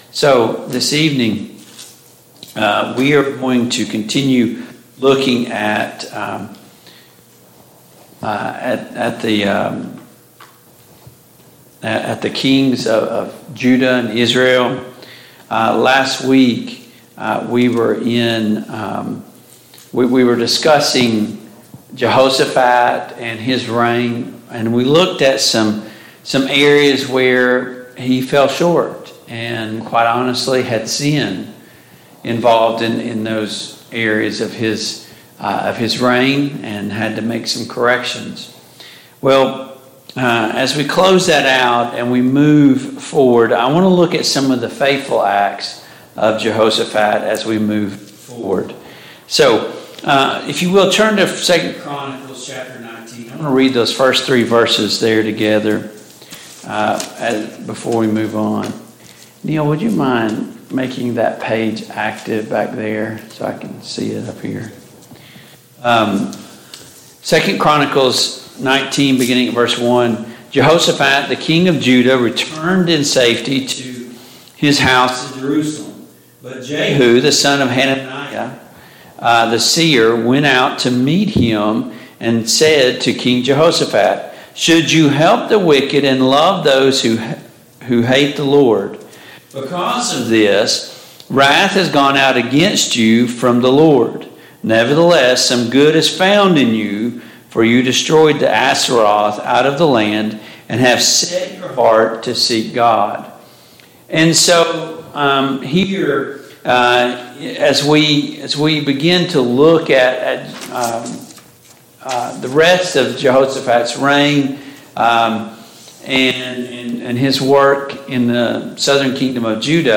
The Kings of Israel Passage: 1 Kings 22, 2 Chronicles 19, 2 Chronicles 20 Service Type: Mid-Week Bible Study